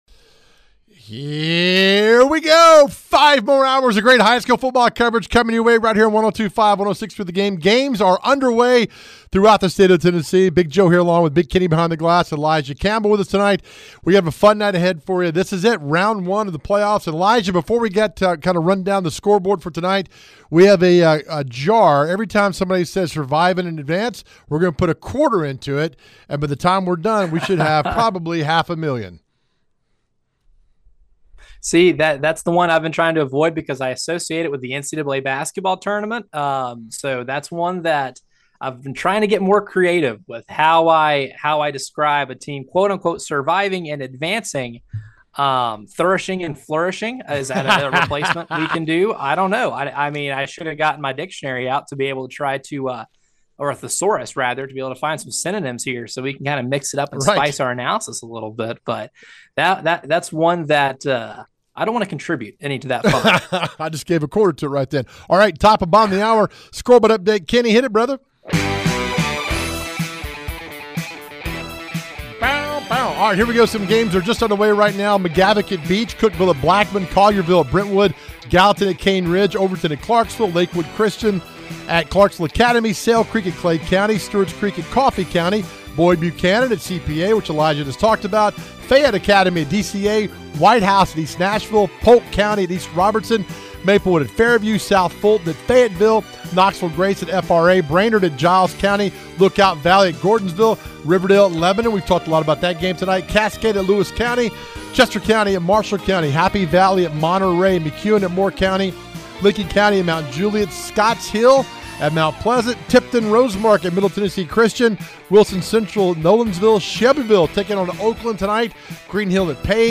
Its playoff time and we have full coverage for you right here with coaches interviews and live game reports as it happens!